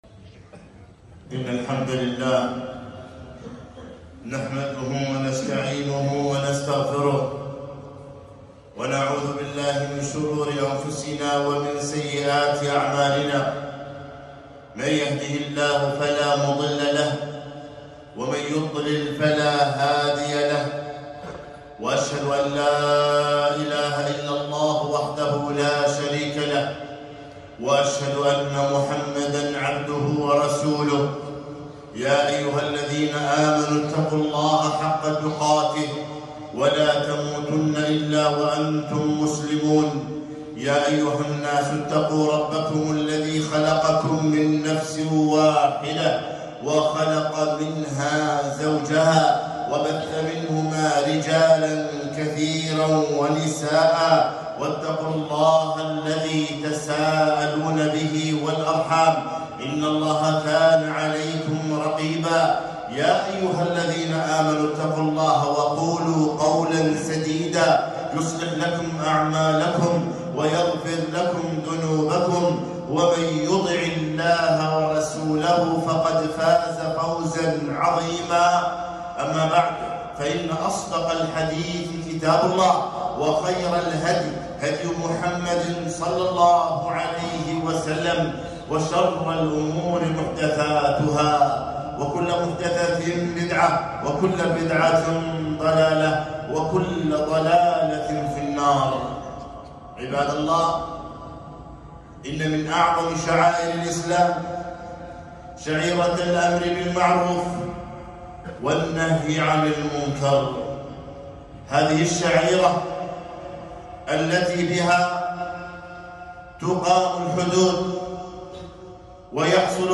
خطبة - الأمر بالمعروف والنهي عن المنكر